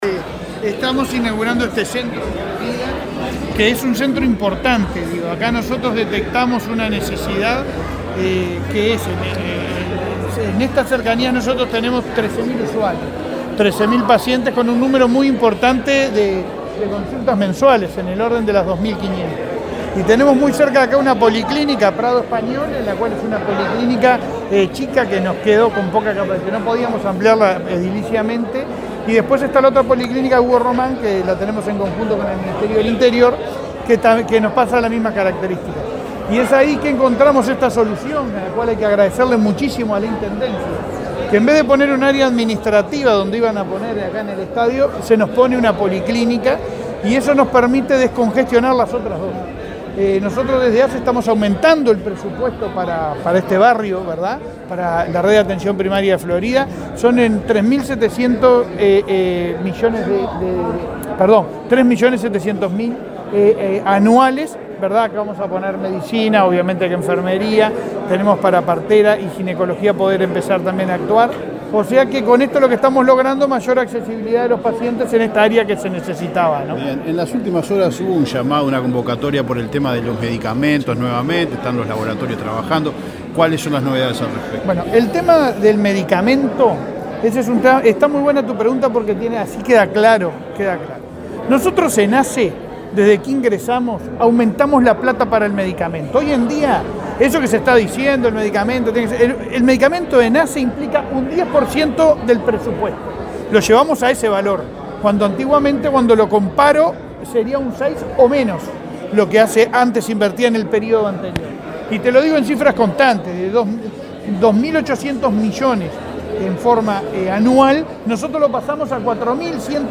Declaraciones a la prensa del presidente de ASSE, Leonardo Cipriani
Luego, Cipriani dialogó con la prensa.